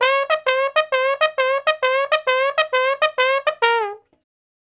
099 Bone Straight (Db) 09.wav